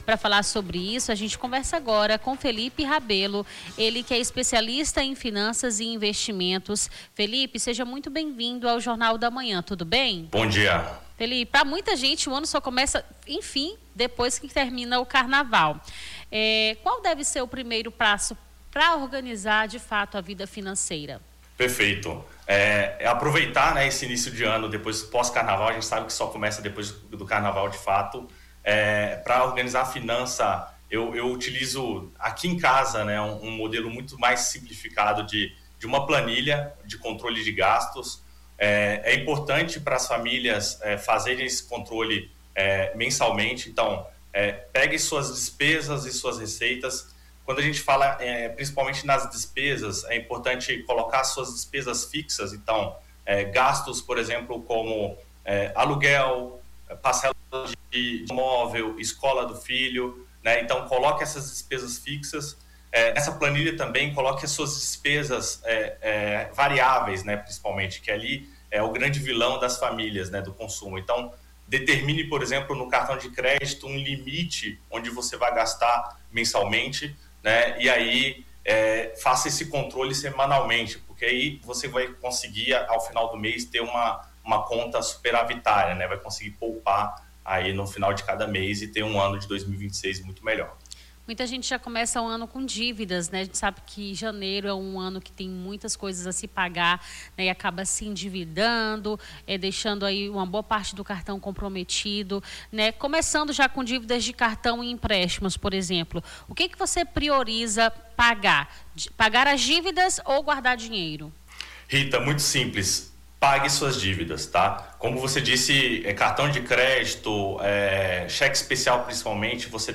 Baixar Esta Trilha Nome do Artista - CENSURA - ENTREVISTA COMO ORGANIZAR AS FINANCAS PARA O ANO NOVO - 26-02-26.mp3 Digite seu texto aqui...